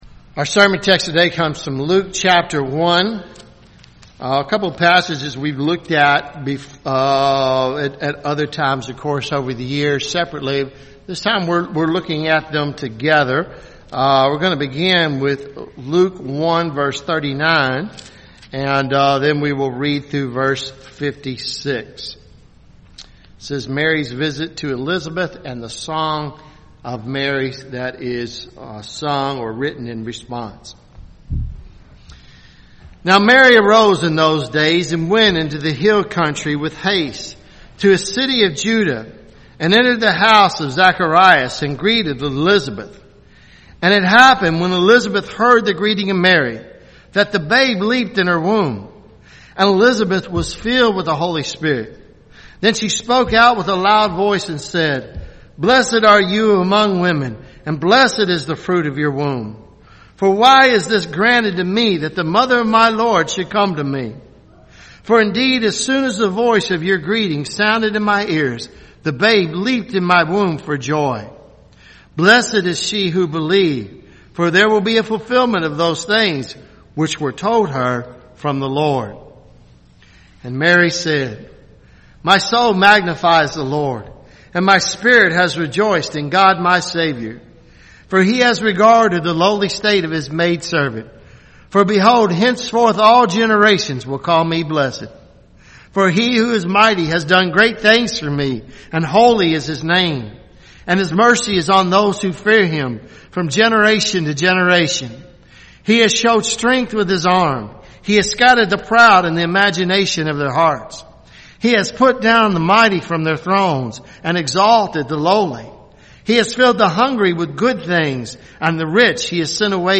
Sermons Dec 22 2020 “The Song of Mary